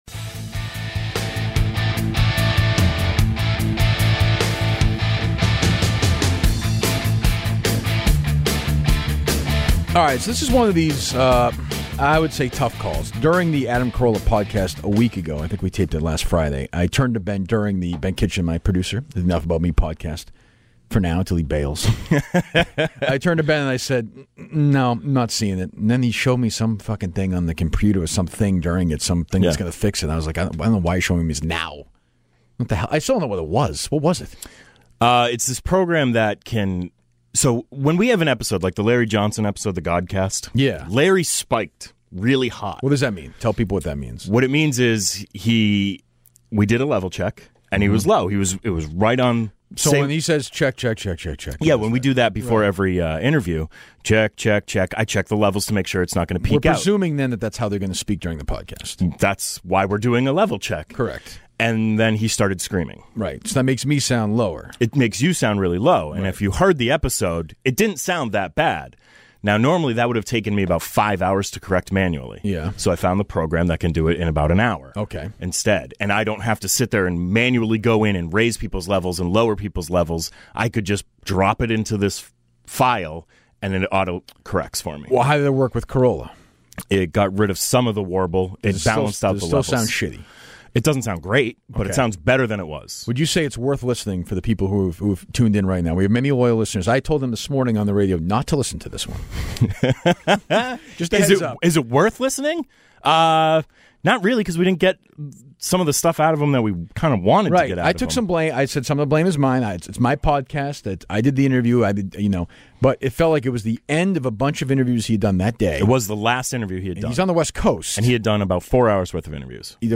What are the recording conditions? The audio quality isn't great but the conversation is.